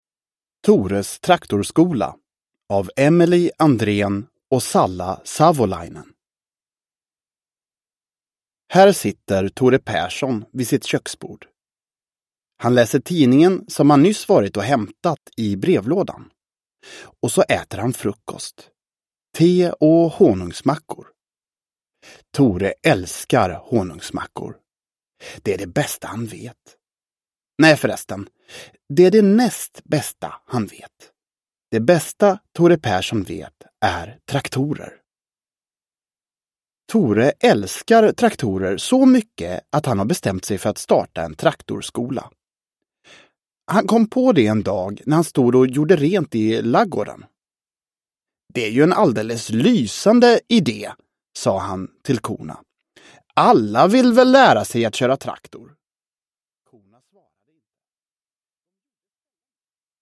Tores traktorskola – Ljudbok – Laddas ner
Uppläsare: Markus Granseth